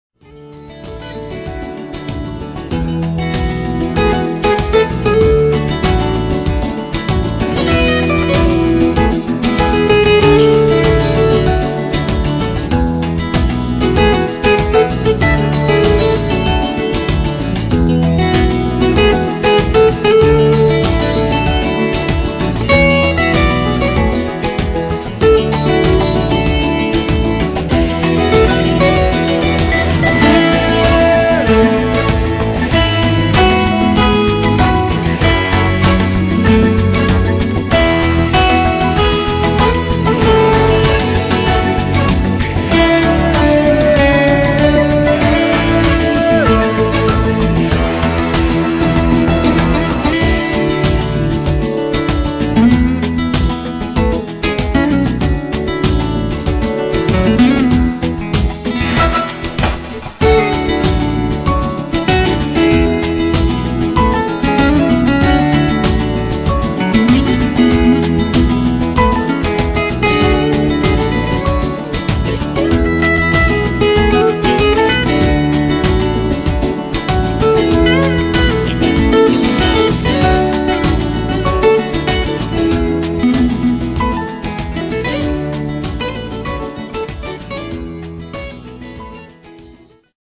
melodična akustična tema